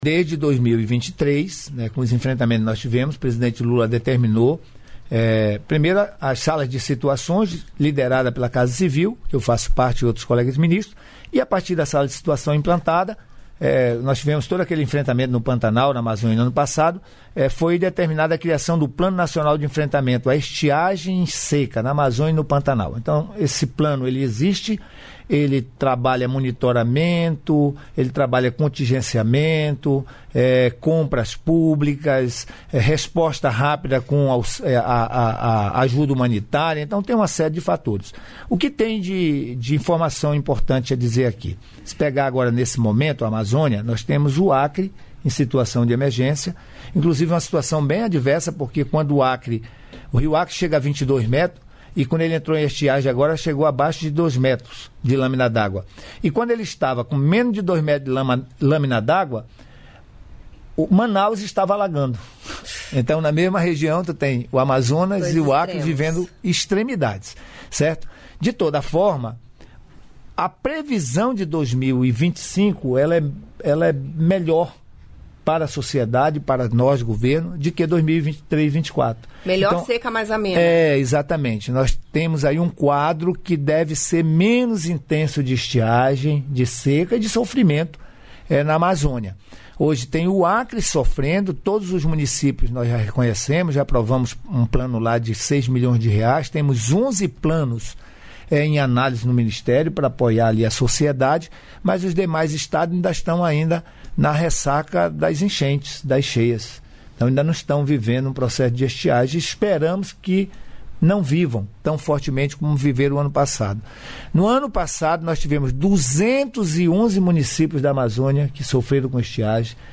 Trecho da participação do ministro da Integração e do Desenvolvimento Regional, Waldez Góes, no programa "Bom Dia, Ministro" desta quinta-feira (28), nos estúdios da EBC em Brasília (DF).